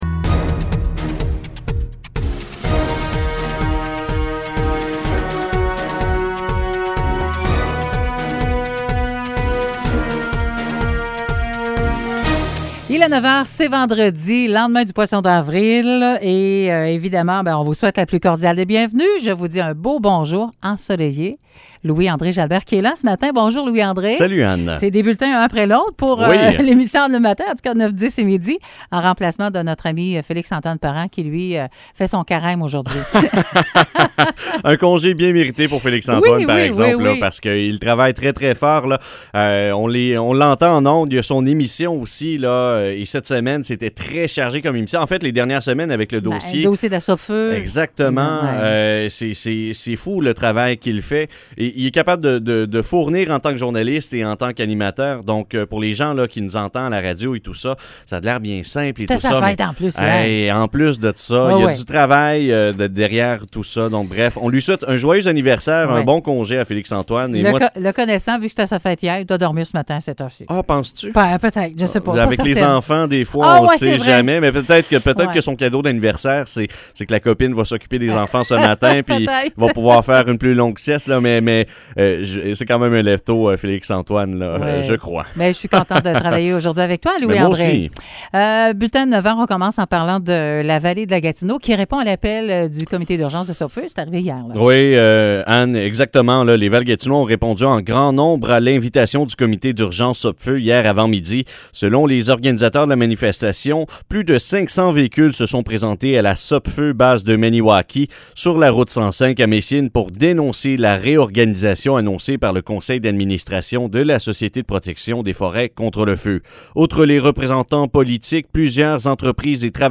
Nouvelles locales - 2 Avril 2021 - 9 h